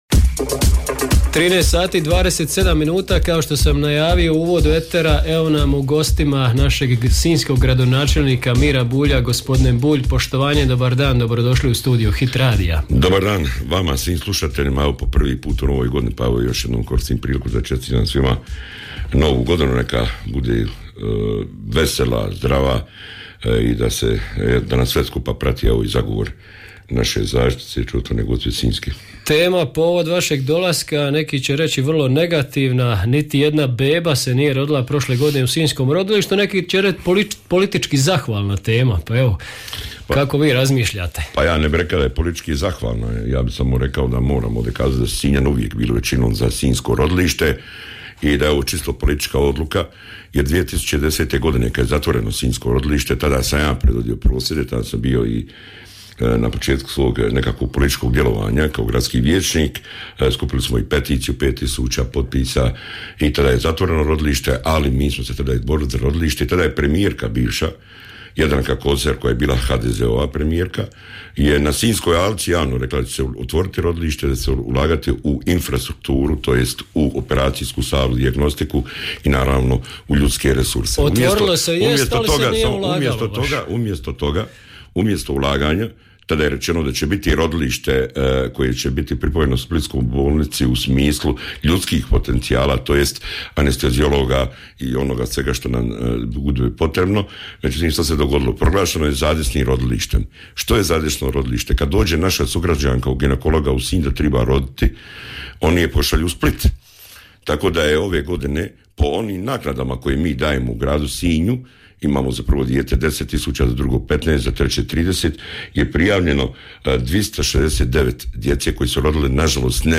Nakon što je objavljen podatak da je u 2023. godini broj poroda u sinjskom rodilištu pao na nulu, gradonačelnik Grada Sinja Miro Bulj je gostovao u programu Hit radija i iznio svoje stavove vezano za ovu temu.